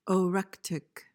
PRONUNCIATION: (o-REK-tik) MEANING: adjective: Relating to appetite or desire.